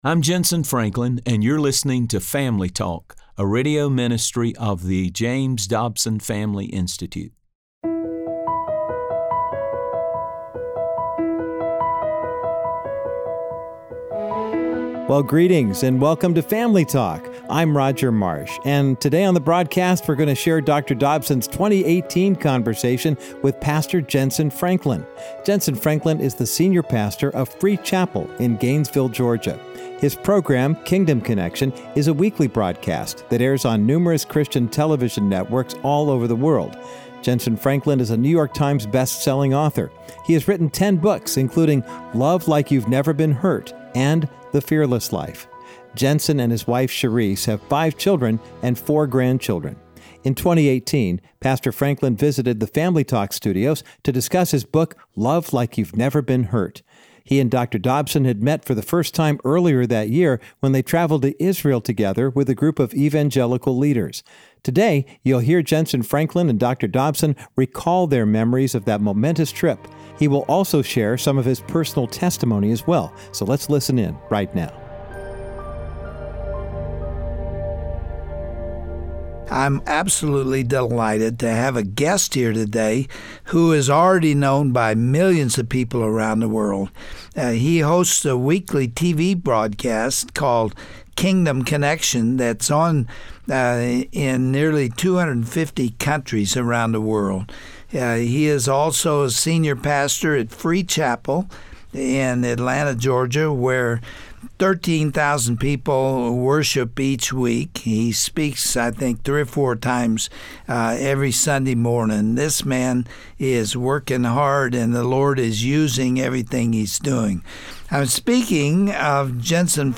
On today’s edition of Family Talk, you’ll hear a heartfelt discussion between Dr. James Dobson and pastor and author, Jentezen Franklin. They reminisce over their upbringings as “preacher’s kids,” and what a powerful impact that had on their lives. Jentezen explains his awe for how the Lord can take ordinary people and use them for His glory.